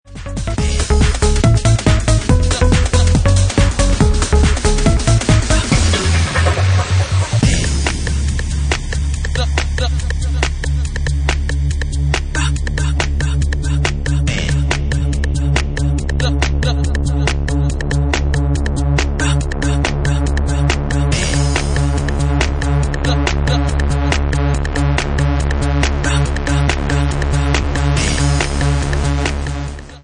Bassline House